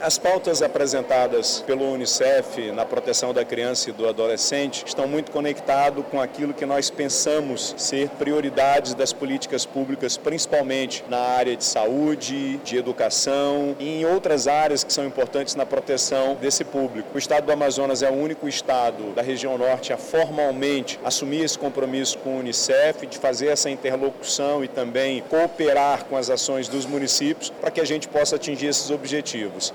Durante a cerimônia de entrega dos selos, o Governador do Amazonas, Wilson Lima, destacou que o Estado assumiu um compromisso na implantação de políticas voltadas à proteção de crianças e adolescentes.